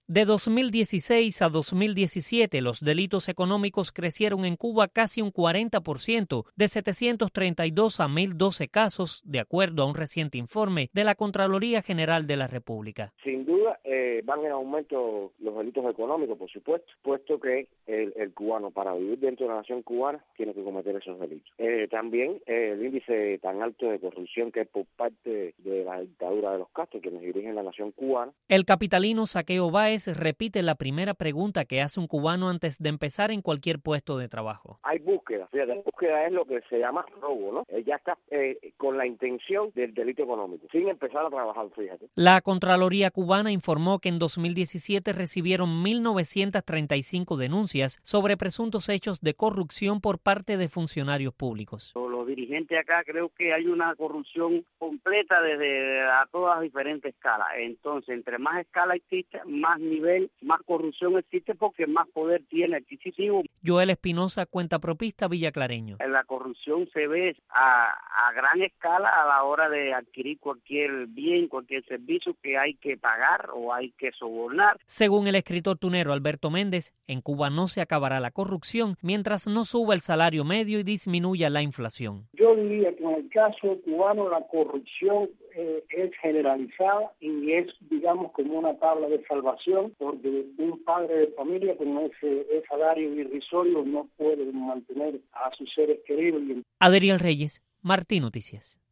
Cubanos consultados por Radio Martí ofrecieron sus opiniones:
Reportaje